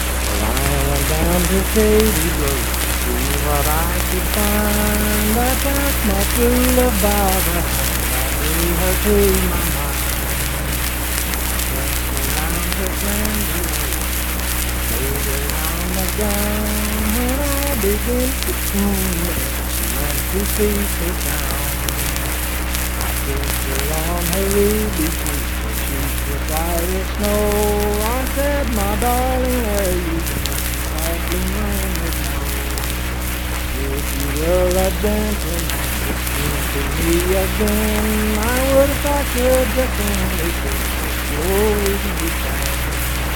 Unaccompanied vocal music
Verse-refrain 4(4).
Voice (sung)
Saint Marys (W. Va.), Pleasants County (W. Va.)